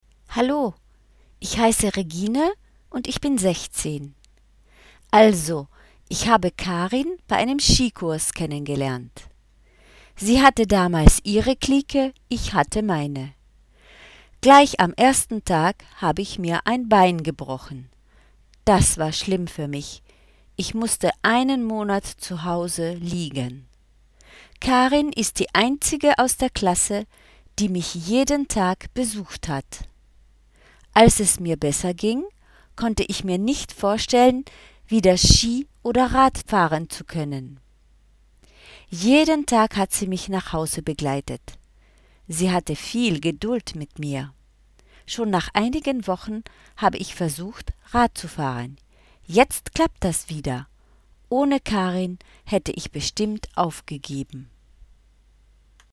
fichiers son de la page du manuel (enregistrés par une collègue d'allemand):